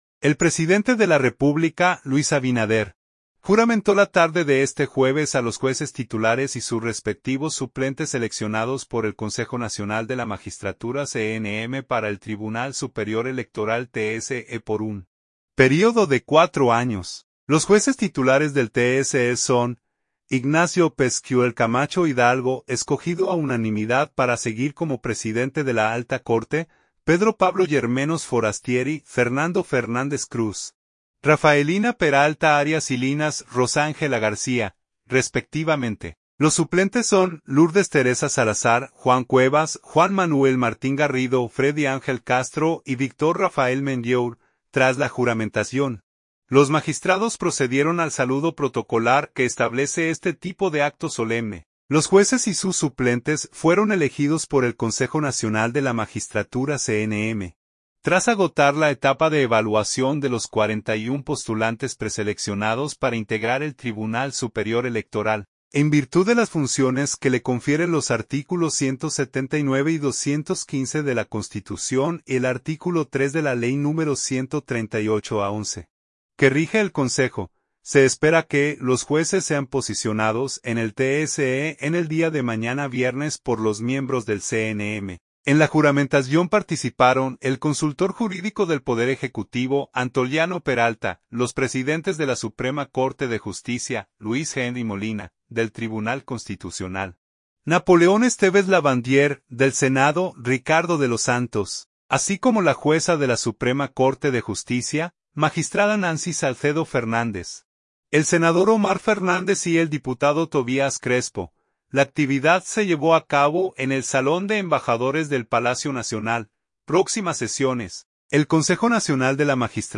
La actividad se llevó a cabo en el Salón de Embajadores del Palacio Nacional.